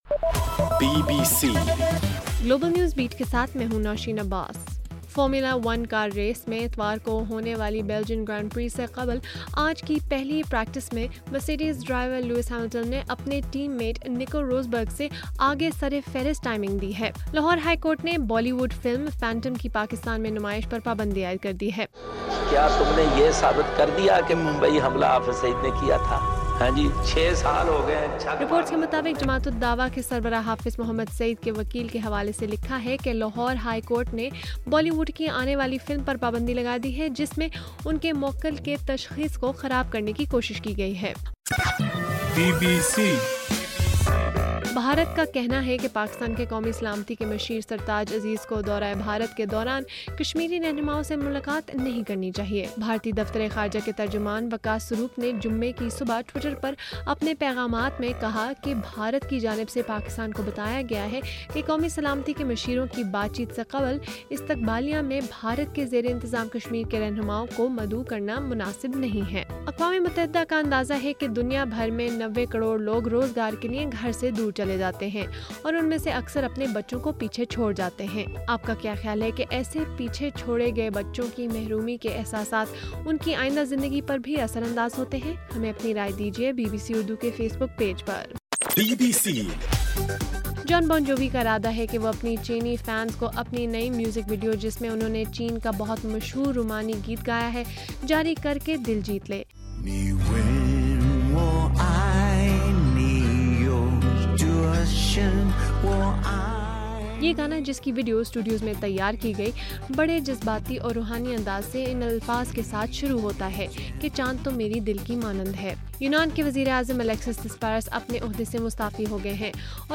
اگست 21: رات 12 بجے کا گلوبل نیوز بیٹ بُلیٹن